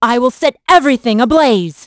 Valance-Vox_Skill3.wav